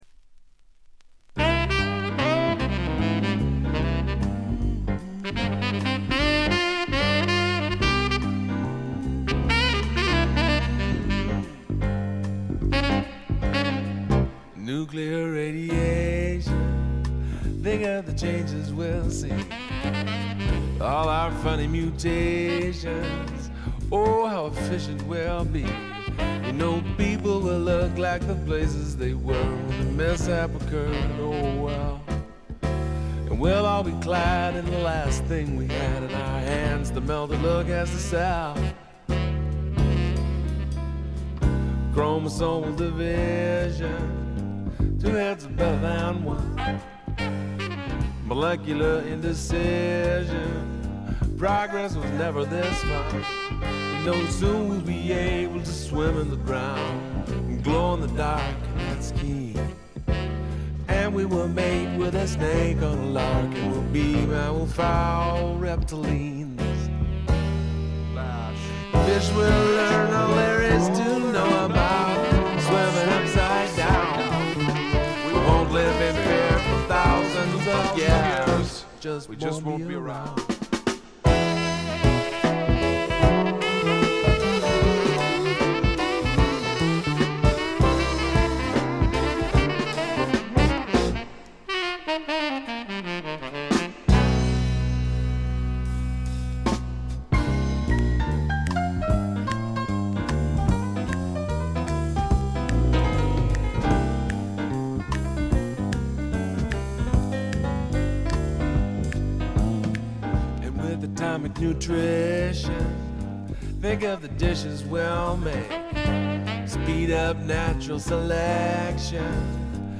試聴曲は現品からの取り込み音源です。
Recorded At - Rex Recording, Portland